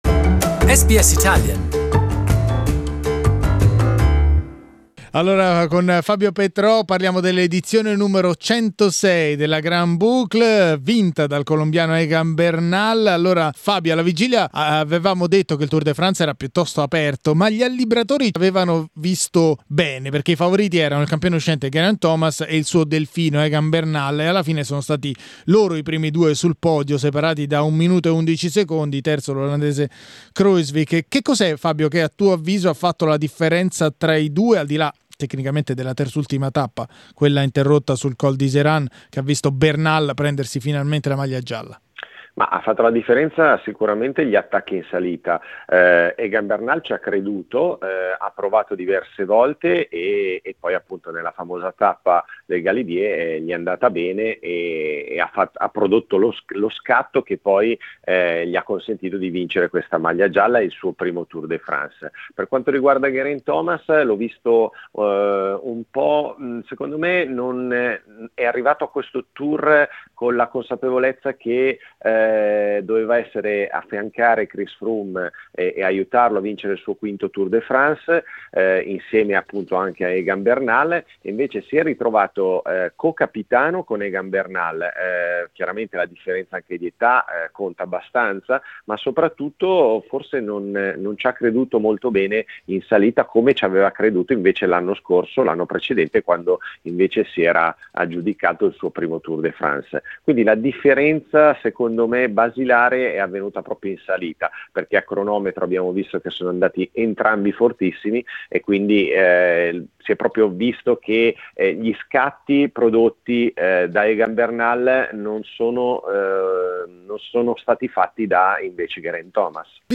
Cycling expert